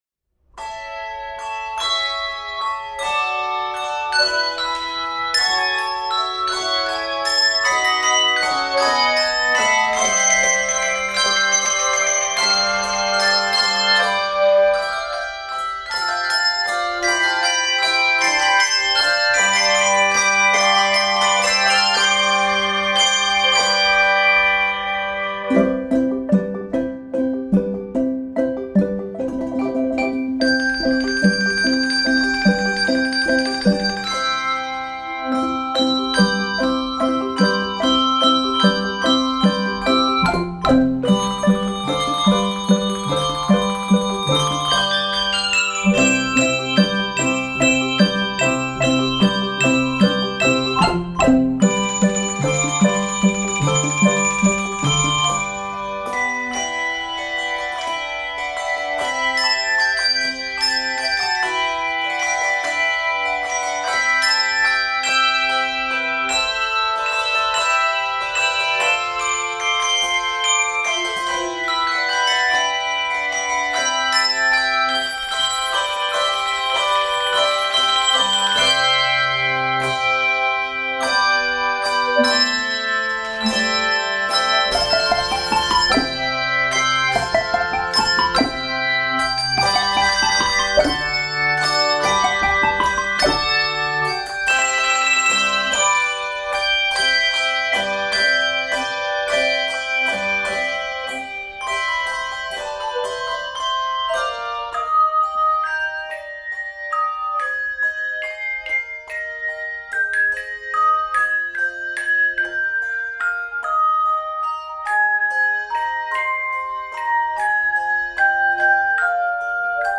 Voicing: 3-7 Octave Handbells and Handchimes